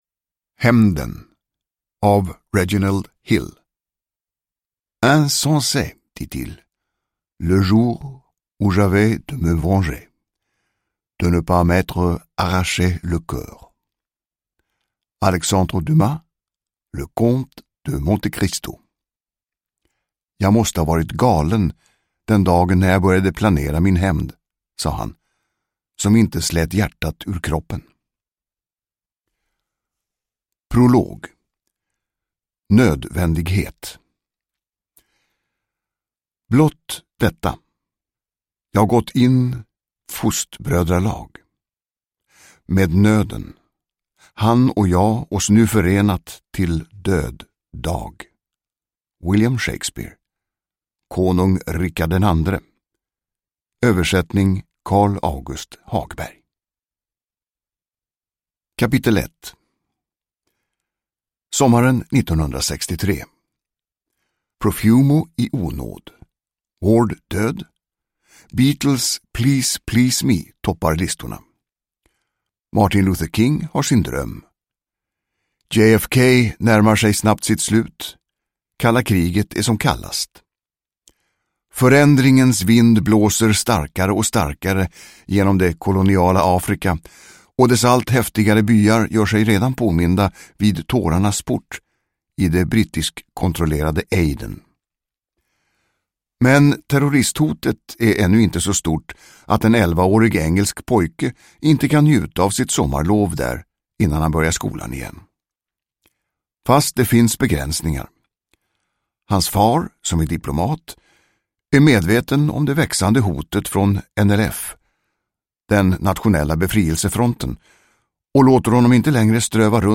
Hämnden – Ljudbok – Laddas ner
Uppläsare: Tomas Bolme